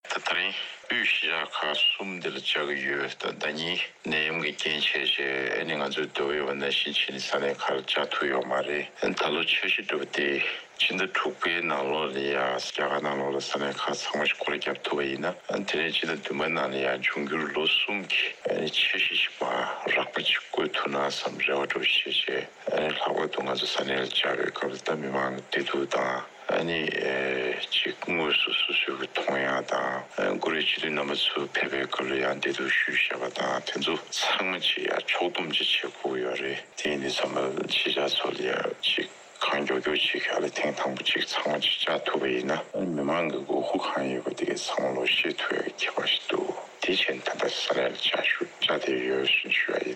སྲིད་སྐྱོང་མཆོག་གིས་ཐེངས་འདིའི་གཞུང་དོན་གཟིགས་སྐོར་གྱི་སྐུ་དོན་ཇི་ཡིན་སོགས་ཀྱི་སྐོར་འདི་ག་རླུང་འཕྲིན་ཁང་ལ་གསུང་དོན།
སྒྲ་ལྡན་གསར་འགྱུར། སྒྲ་ཕབ་ལེན།